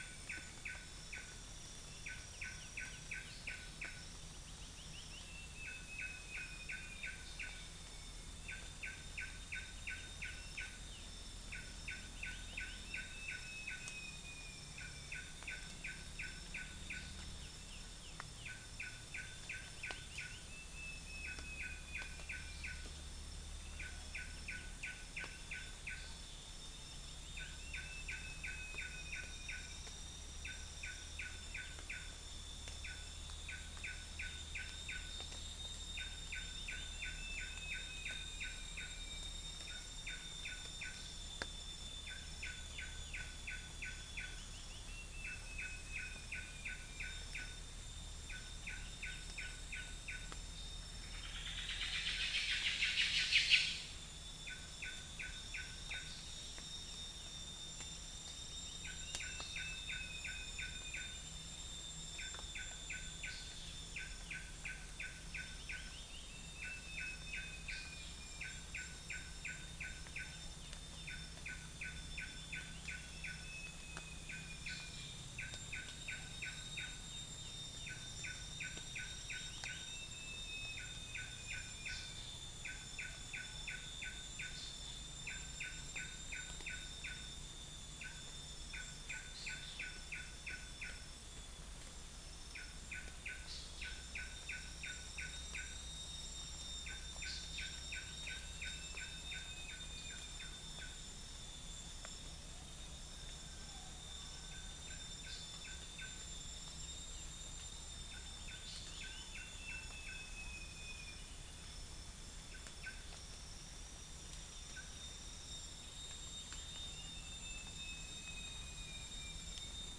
Upland plots dry season 2013
unknown bird
Mixornis gularis
Malacopteron magnirostre
Pellorneum nigrocapitatum